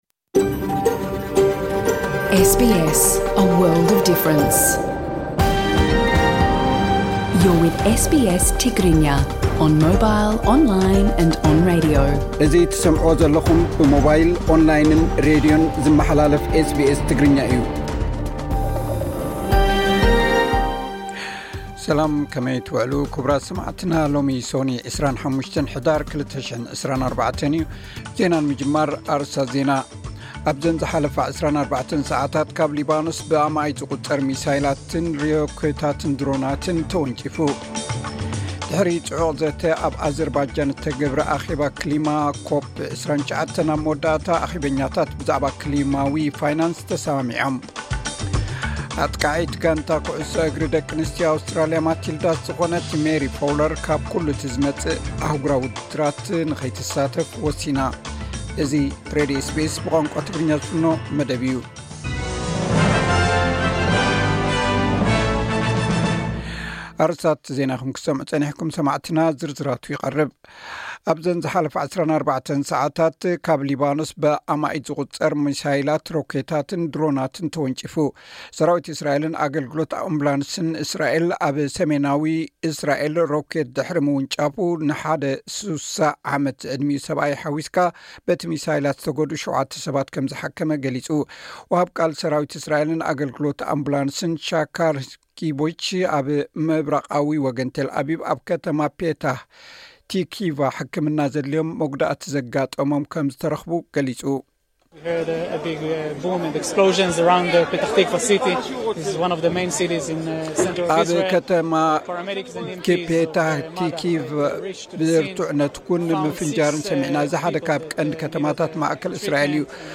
ዕለታዊ ዜና ኤስ ቢ ኤስ ትግርኛ (25 ሕዳር 2024)